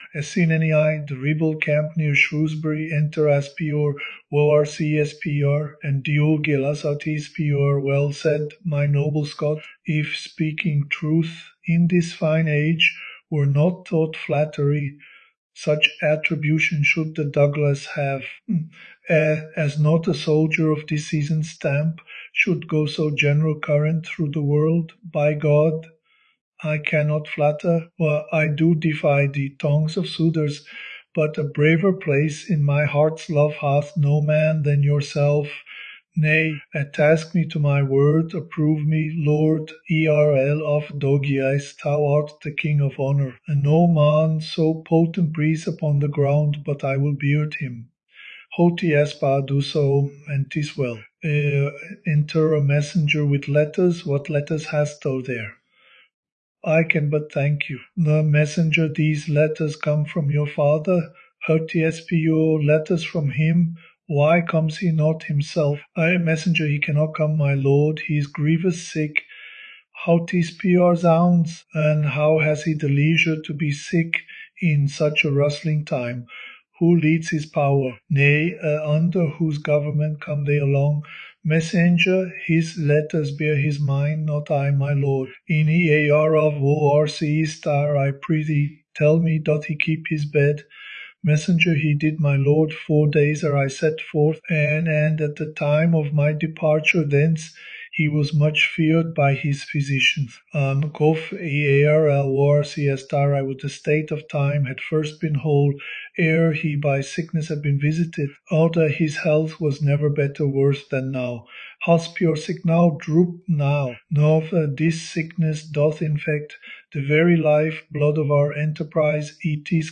KI-Stimmentechnologie Experiment jenseits von Shakespeare ... (m)ein erster »Voice Clone« liest dann einmal vor ...
Ein KI Selbstversuch 15 Sekunden Audio Stimme als Referenz & 4321 Sekunden Text-to-Speech (TTS) Technologie KI-Processing lokal auf einem M1 Apple MacMini mit 16GB Arbeitspeicher.
Höre hier wie sich der Clone-Rezitator schlägt (Englischer Shakespeare Buchtext unten zum Mitlesen ...)